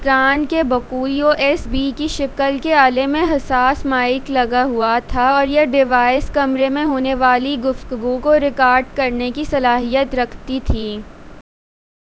deepfake_detection_dataset_urdu / Spoofed_TTS /Speaker_10 /114.wav